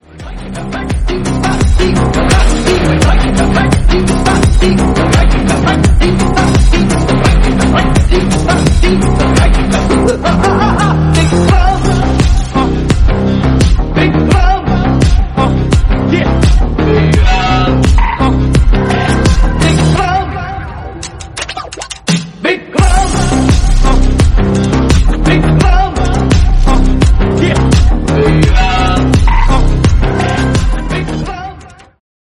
intense background music